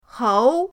hou2.mp3